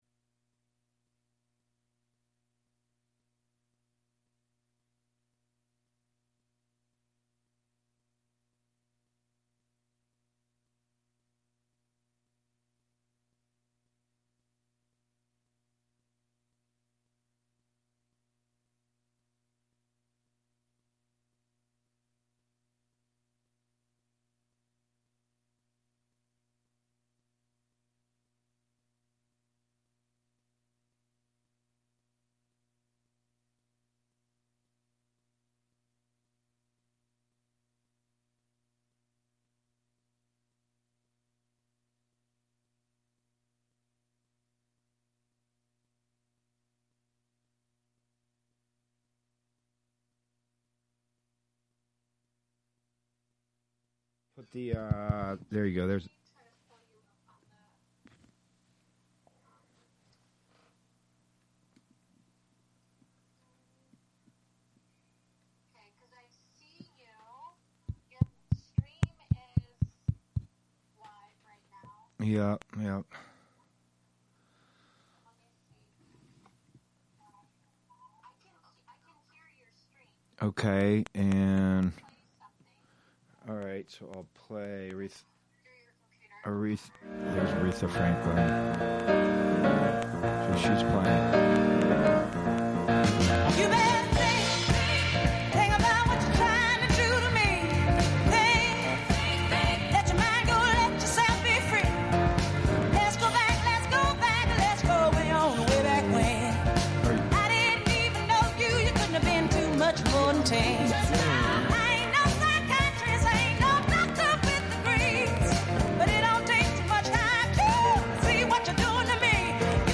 Prime Jive: Monday Afternoon Show- Live from Housatonic, MA (Audio)
broadcasts live with music, call-ins, news, announcements, and interviews